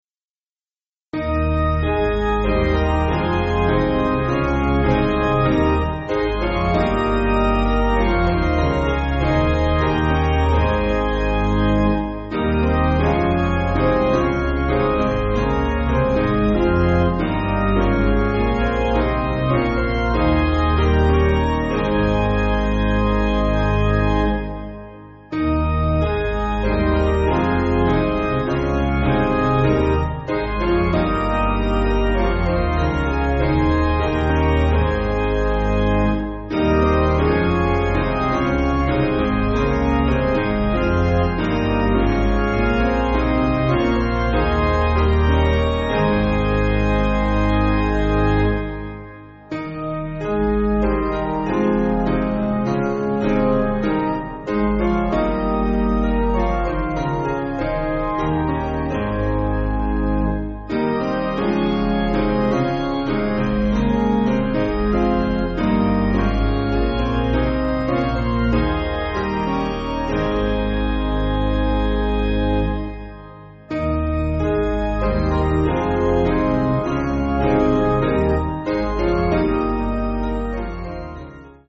Basic Piano & Organ
(CM)   6/Ab